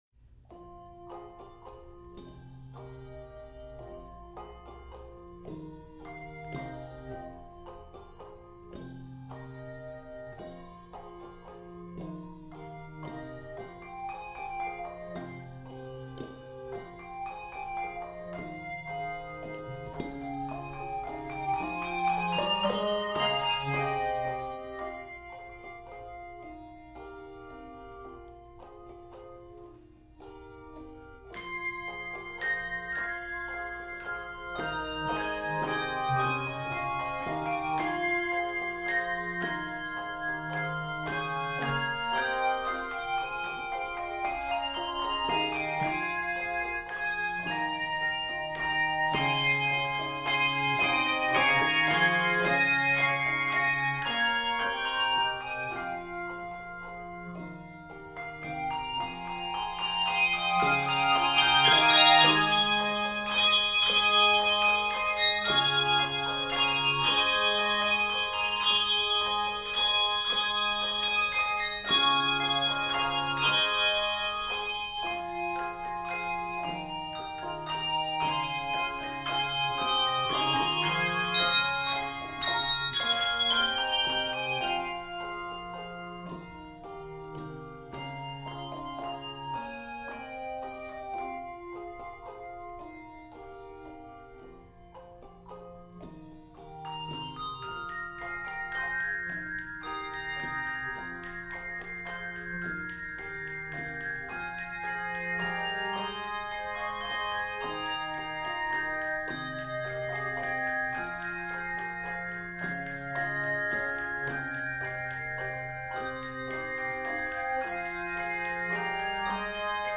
ebullient setting
Judicious use of thumb-damp keeps the texture light.
It is arranged in f minor.